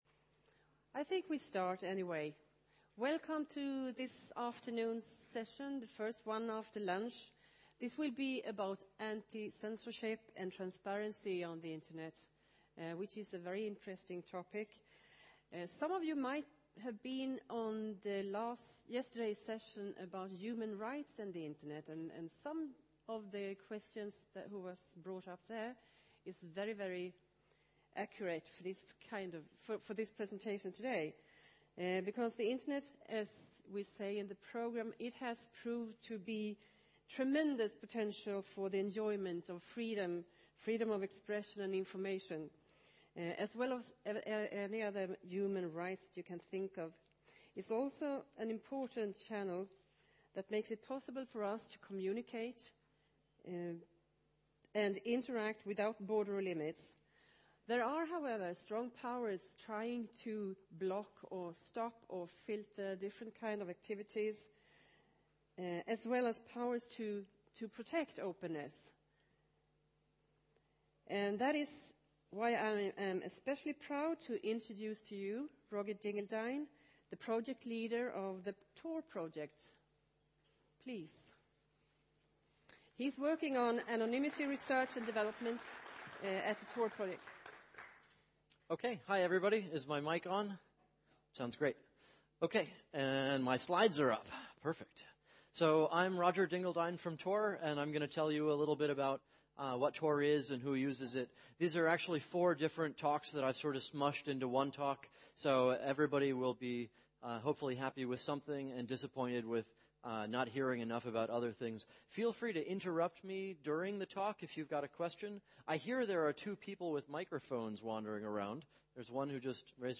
Plats: Kongresshall A